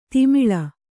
♪ timiḷa